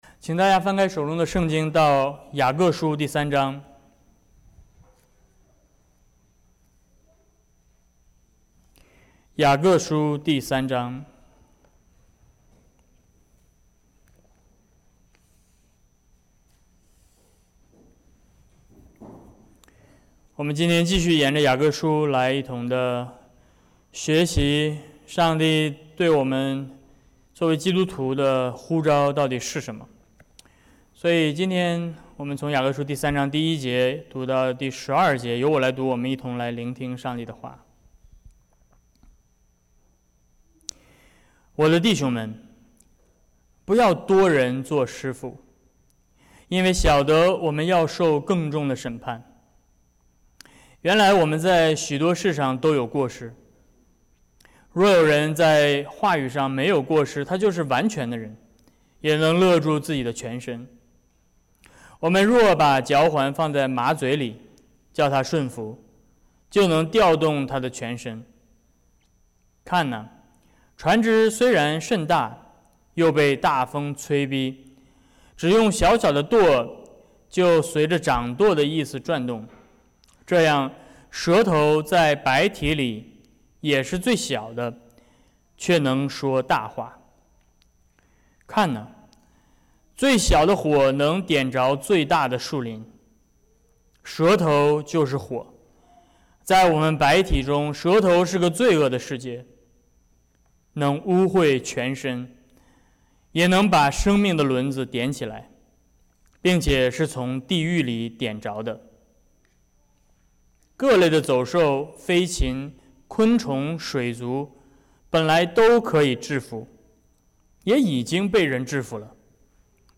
Series: 雅各书系列 Passage: 雅各书3:1-12 Service Type: 主日讲道 June 09